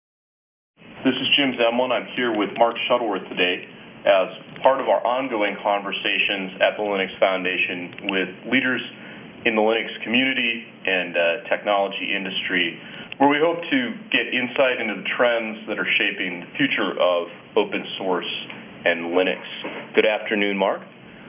As reference speech fragment the part of Mark Shuttleworth's interview was given.